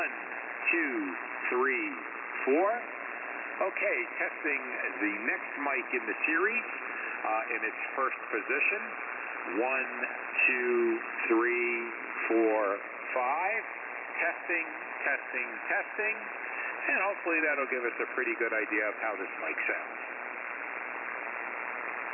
• Sound tinny
• Punchy on high end
• Harsh, no lows, very tinny
• Very low gain
Heil HM10XD Wide